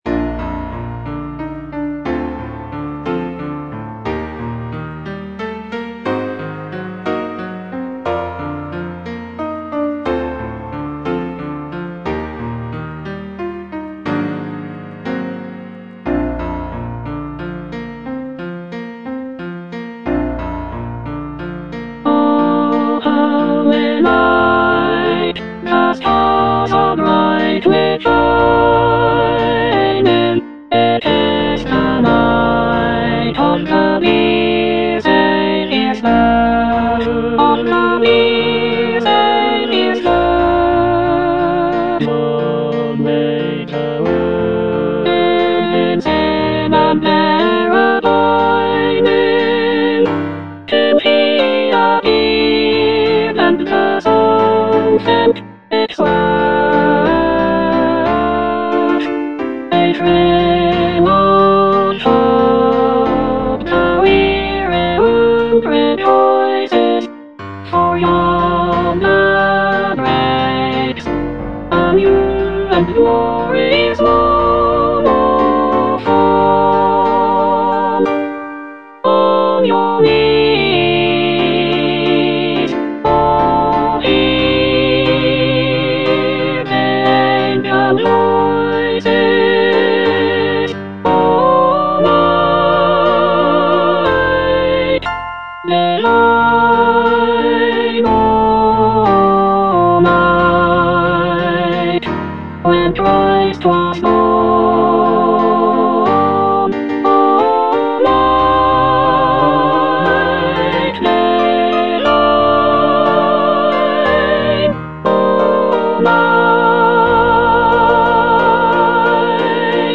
Soprano I (Emphasised voice and other voices)